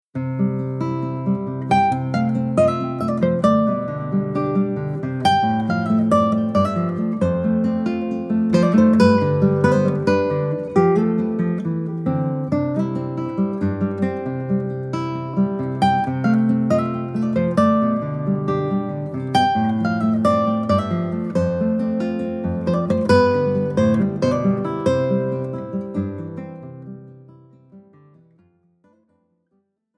Sechs Jahrhunderte Gitarrenmusik für Gitarrenduo
Besetzung: 2 Gitarren
POP